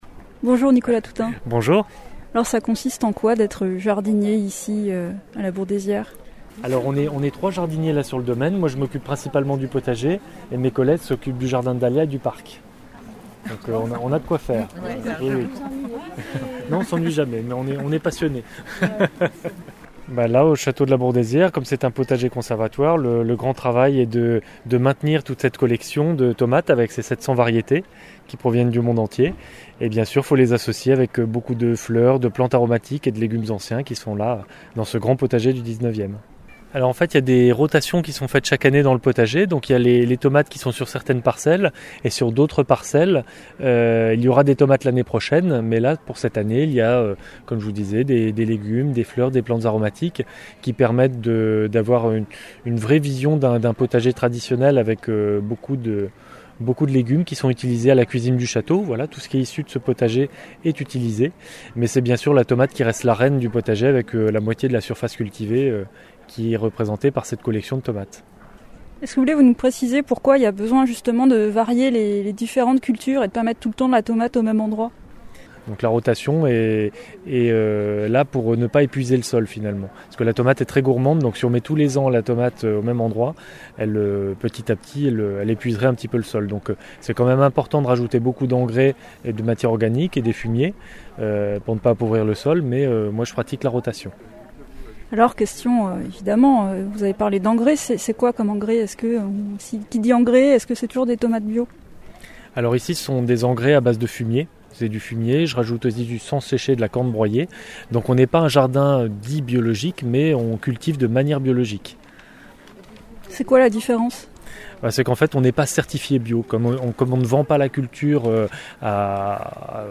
Direction Montlouis-sur-Loire, Château de la Bourdaisière, pour le 24e festival de la tomate et des saveurs.